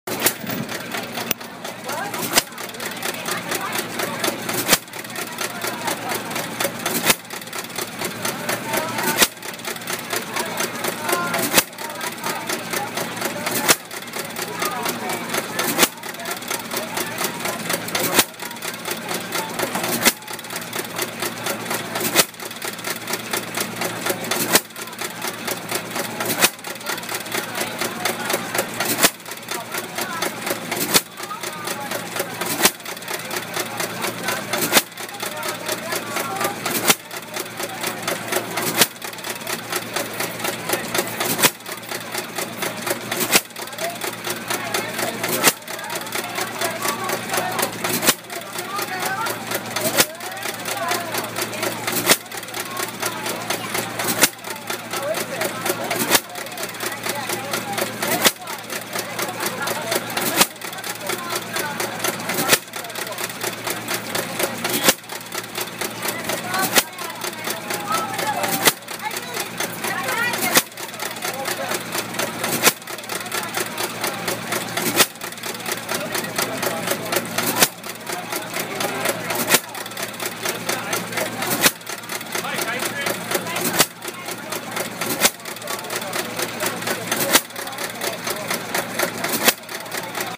Vintage ice cream maker
One stroke engine ice cream maker at a small town fair in Vermont, 2014. This is part of the Obsolete Sounds project , the world’s biggest collection of disappearing sounds and sounds that have become extinct – remixed and reimagined to create a brand new form of listening.